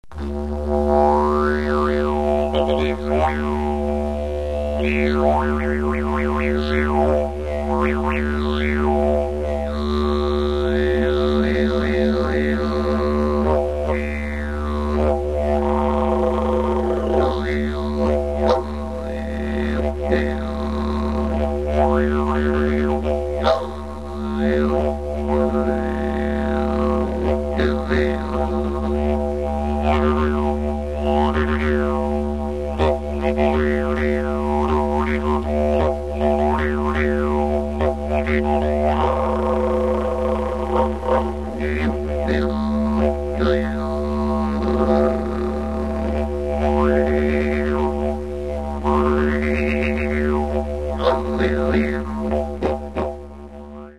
It is also equally rewarding to play in a freeform manner dropping in and out of rhythmic patterns and creating rich and varied drone sequences.
freeform.mp3